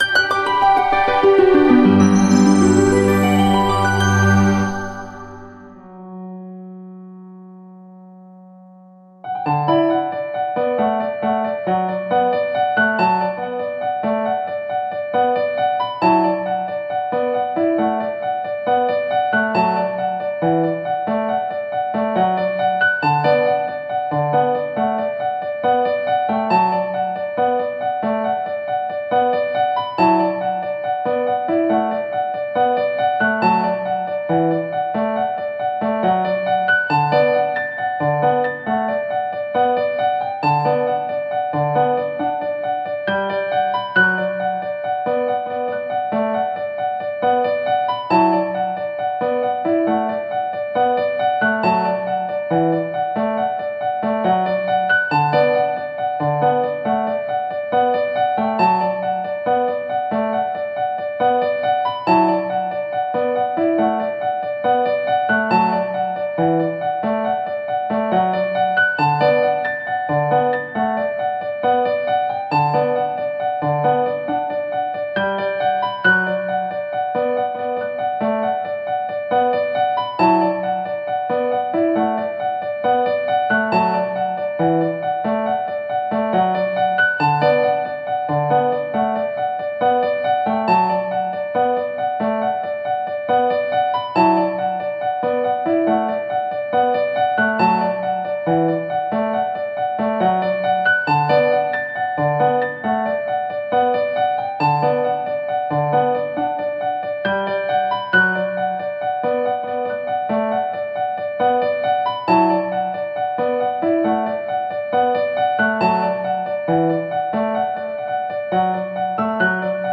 ↓↓↓↓   【入場音】
EnterBGM120s.mp3